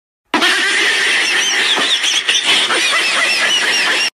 Download Funny Dog sound effect for free.
Funny Dog